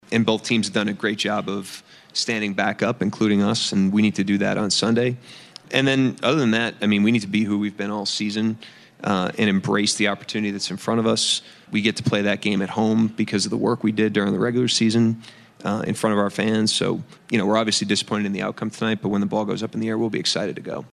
Head coach Mark Daigneault gave credit to the Nuggets postgame.